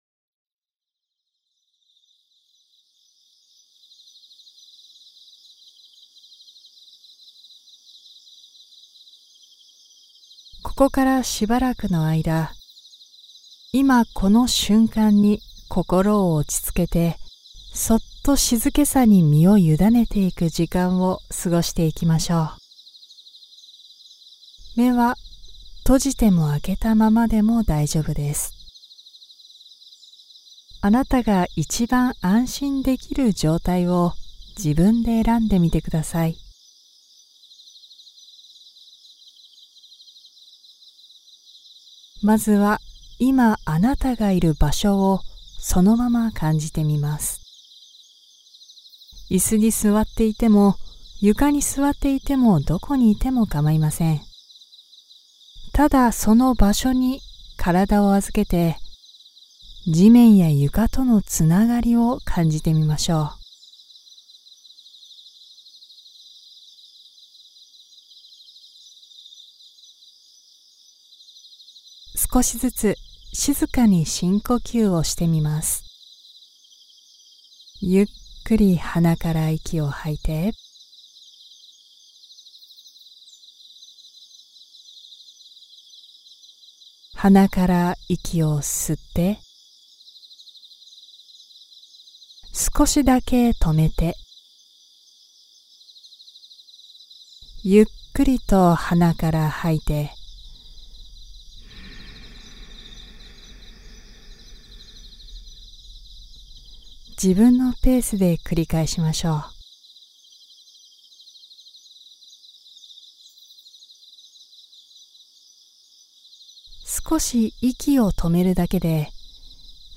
自然音とともに 夕暮れの静けさ瞑想